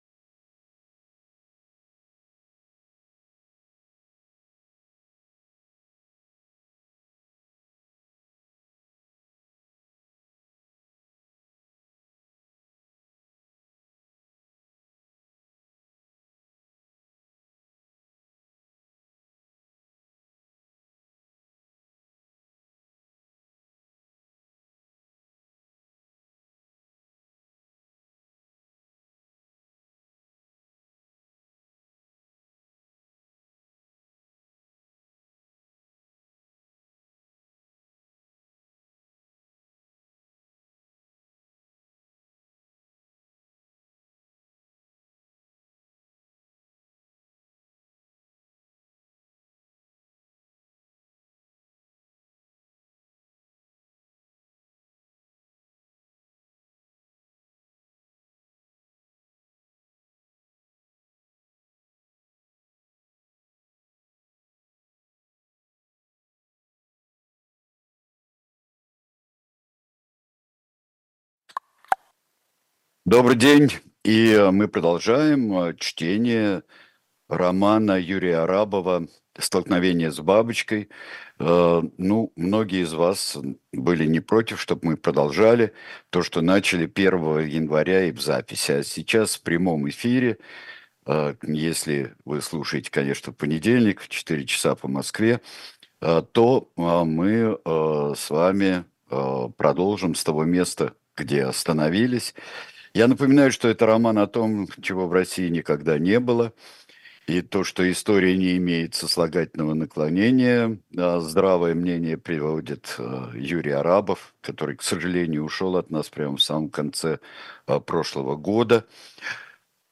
Сергей Бунтман читает роман Юрия Арабова